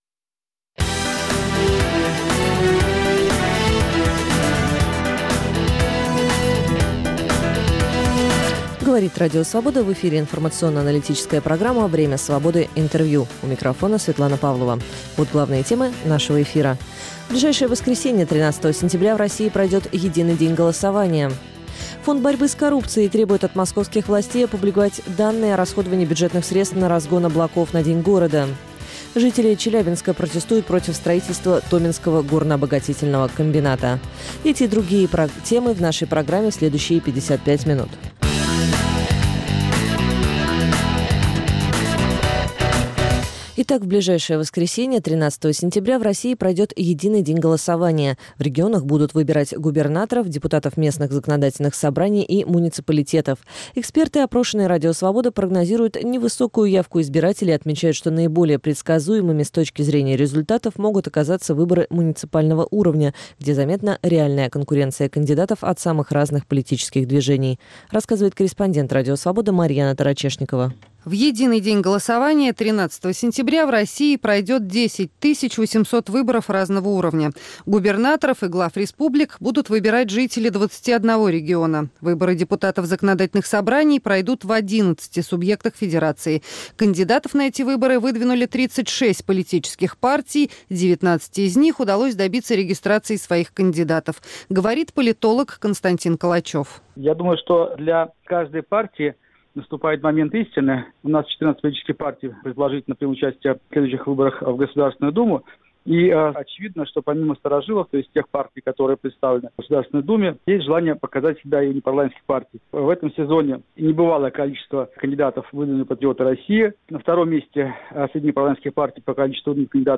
Время Свободы - Интервью